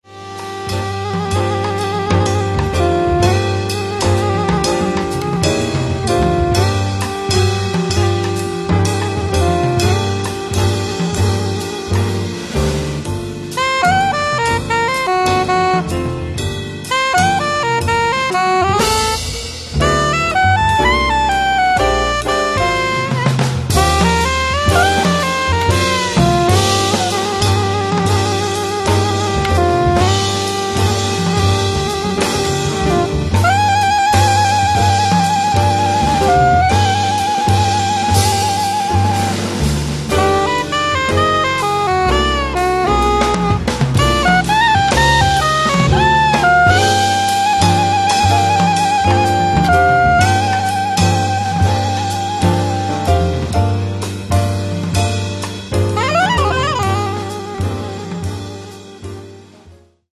sax alto e sopranino
pianoforte
contrabbasso
batteria